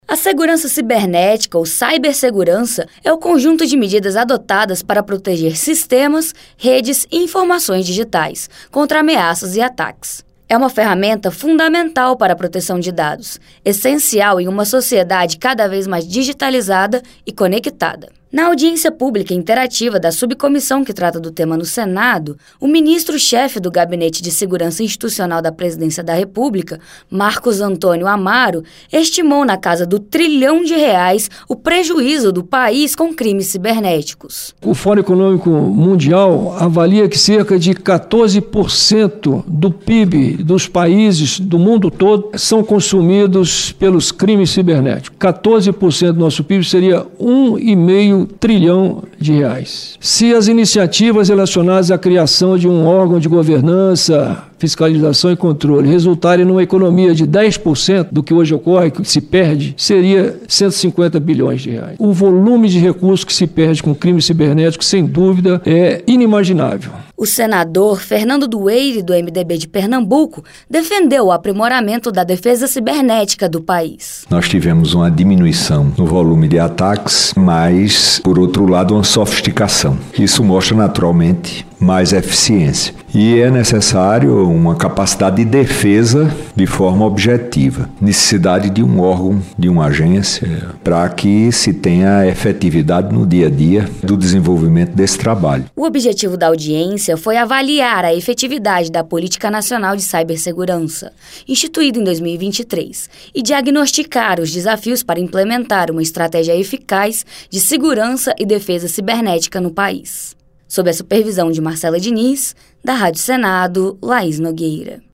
A Subcomissão Permanente de Defesa Cibernética da Comissão de Relações Exteriores e Defesa Nacional do Senado Federal debateu nesta terça-feira (18), em audiência pública, a Política Nacional de Cibersegurança.